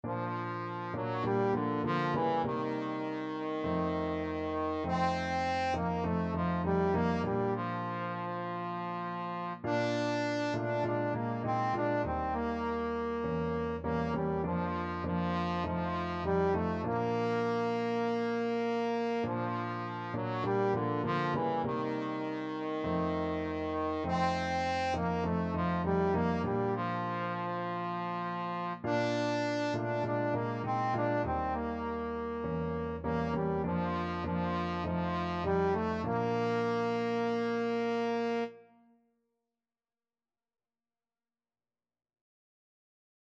Trombone version
4/4 (View more 4/4 Music)
Moderato